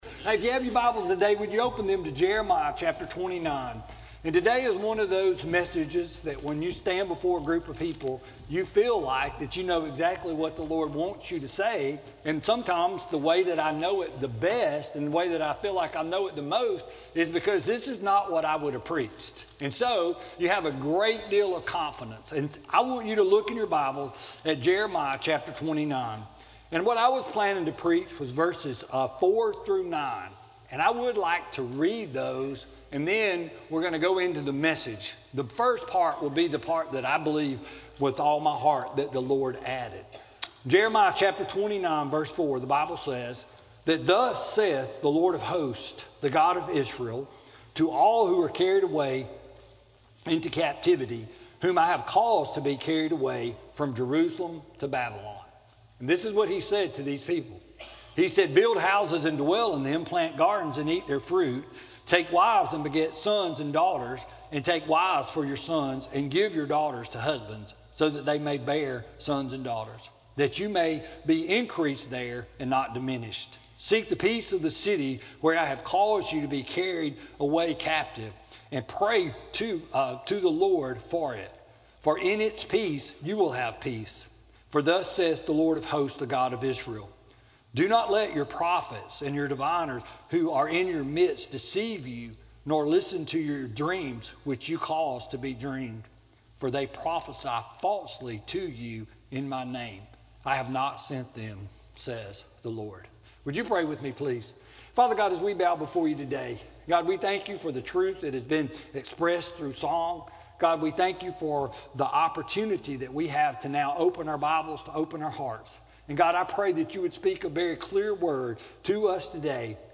Sermon-4-7-19-CD.mp3